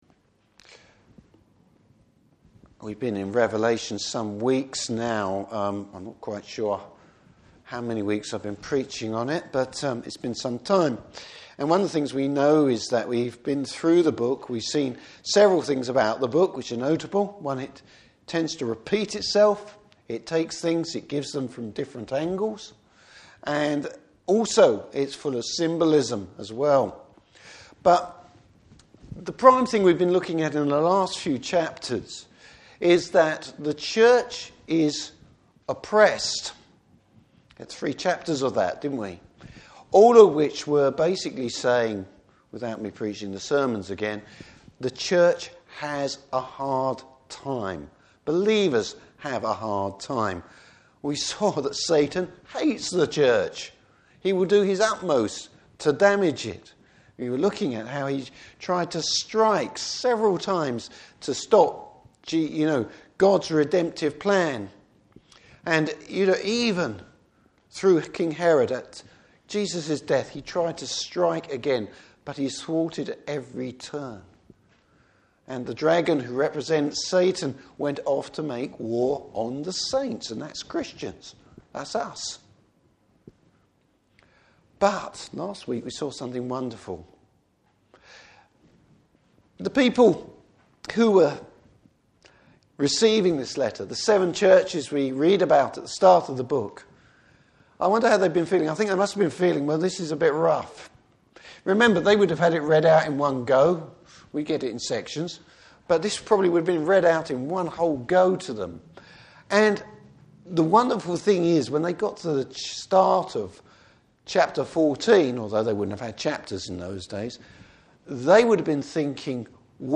Service Type: Evening Service Bible Text: Revelation 14:14-20.